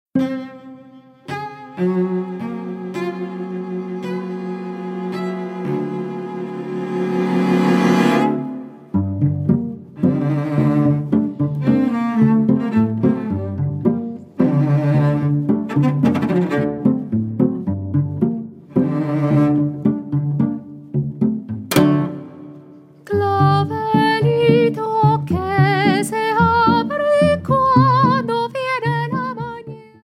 GRABADO EN planet estudio, Blizz producciónes
SOPRANO
VIOLONCELLO SOLO Y ENSAMBLES